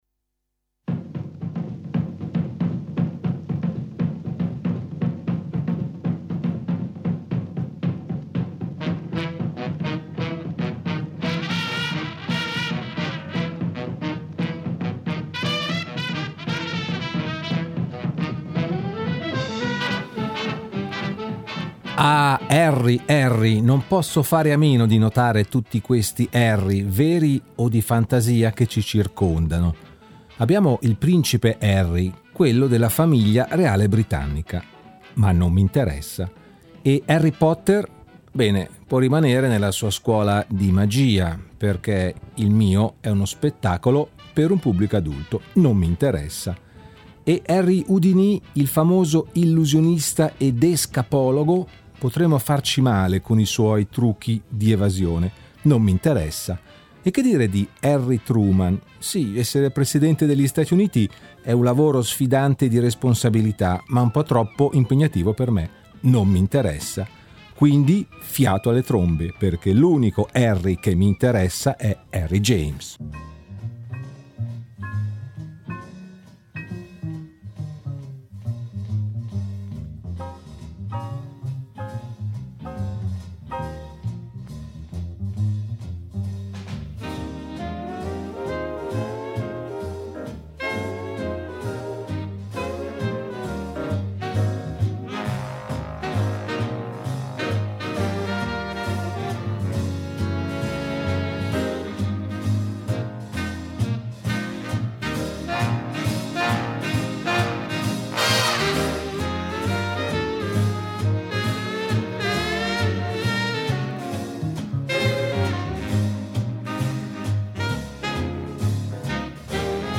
Anche fantasmi, streghe e vampiri hanno trovato il loro ritmo sulle note del clarinetto, delle trombe, del basso e della batteria . In questa mia puntata il lato misterioso e soprannaturale dello swing !